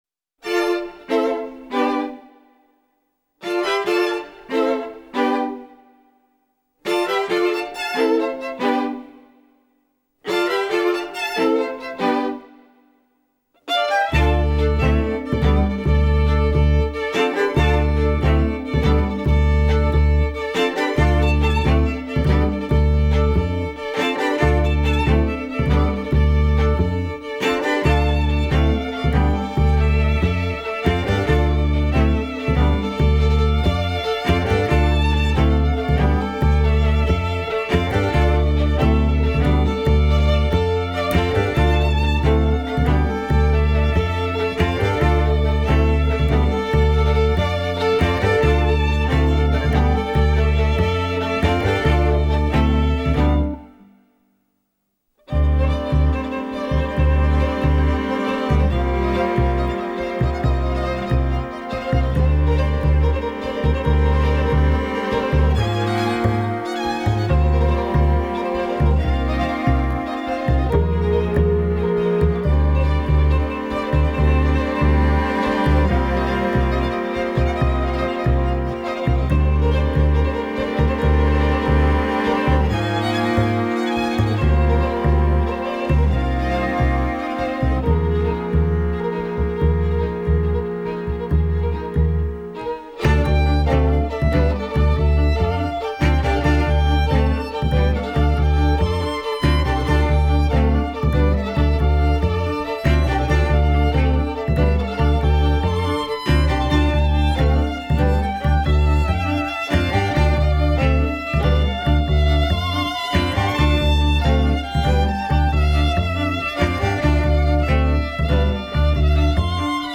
это секстет струнных
три скрипки, альт, виолончель и контрабас